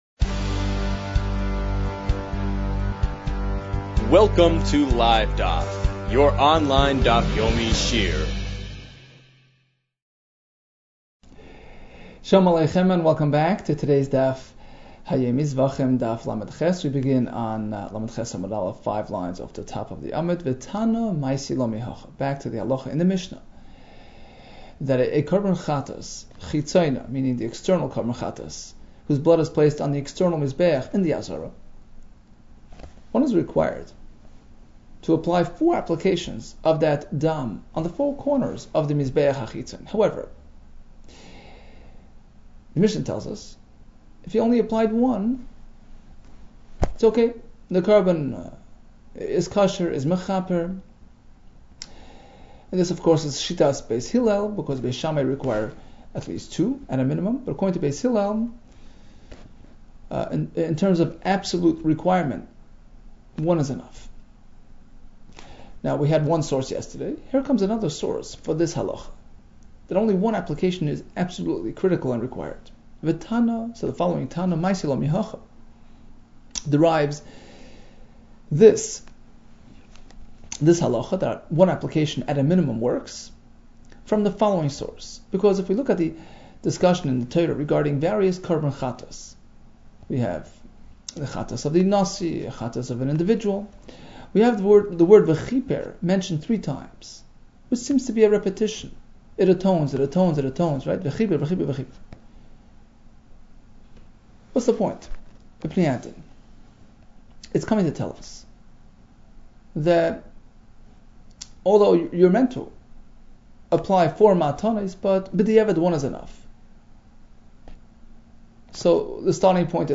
Zevachim 38 - זבחים לח | Daf Yomi Online Shiur | Livedaf